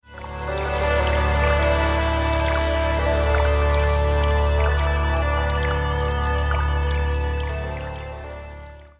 One of the first polyphonic analog synthesizer.
This synth has two SEM module which has each 2-VCO, 1 VCF, 2 enveloppes and 8 memory program.
Demo Excerpt (Vintage Synths)
oberheim_sem_vintagesynths_excerpt.mp3